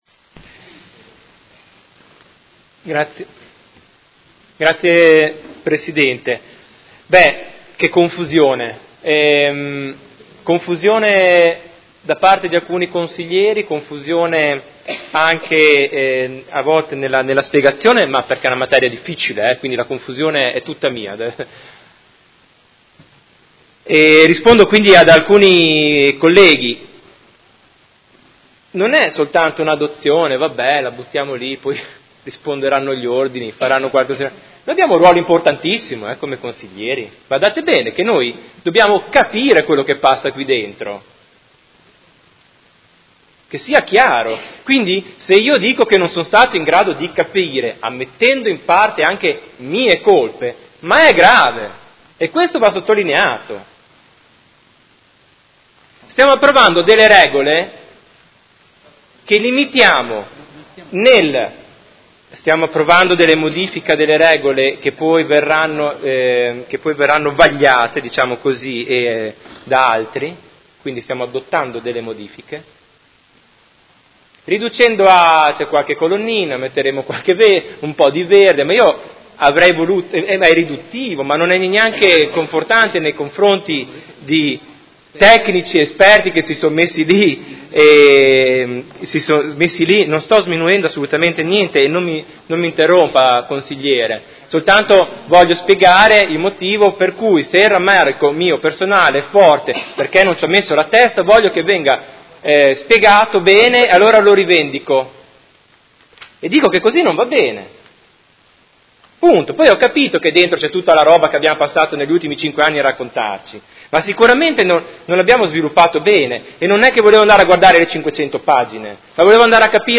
Seduta del 04/04/2019 Dichiarazione di voto.